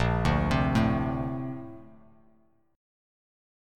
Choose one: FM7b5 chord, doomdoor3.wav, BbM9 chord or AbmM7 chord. BbM9 chord